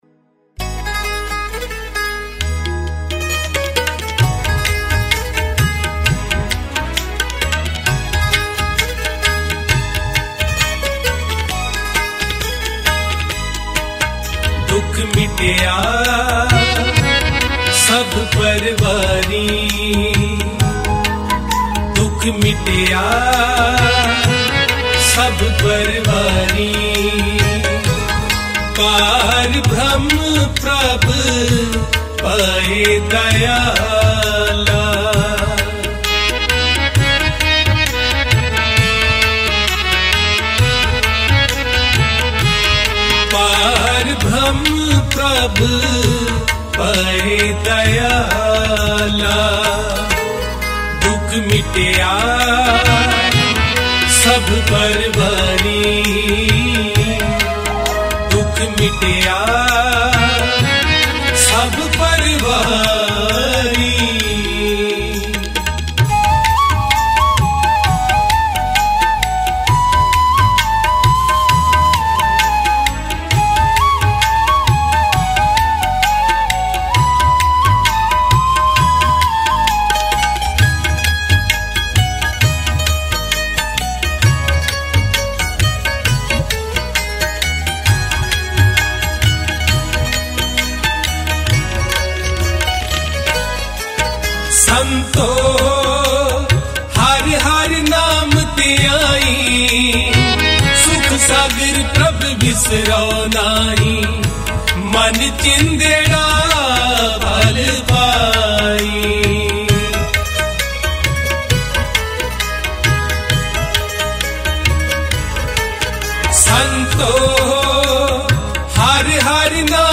Mp3 Files / Gurbani Kirtan / 2025 Shabad Kirtan /